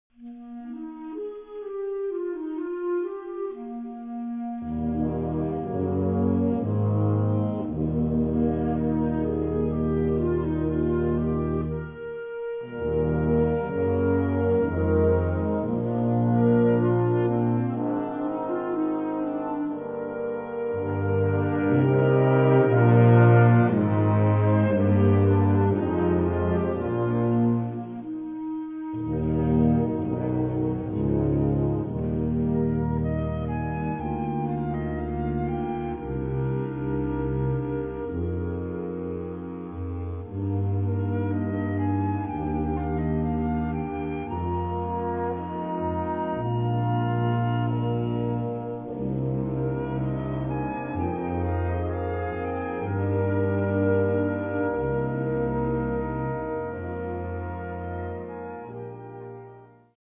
Gattung: - ein Landschaftsbild
Besetzung: Blasorchester